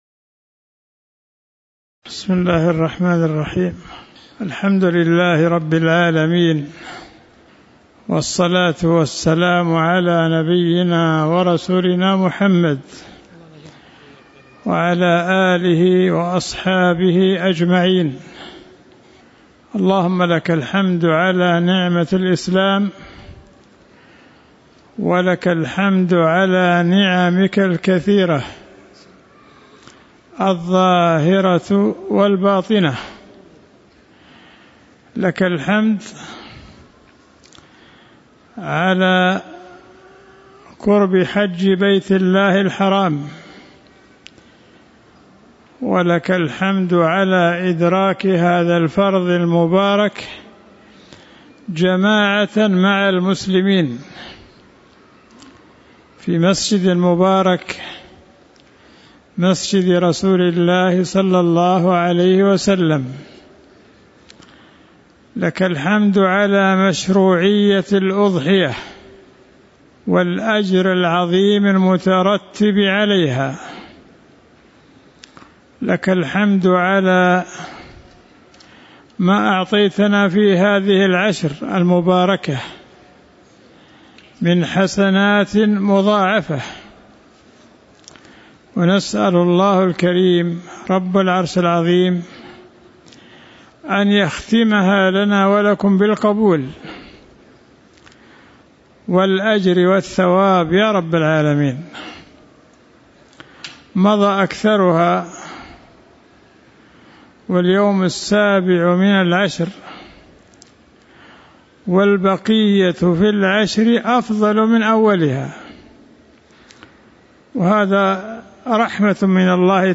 تاريخ النشر ٨ ذو الحجة ١٤٤٠ المكان: المسجد النبوي الشيخ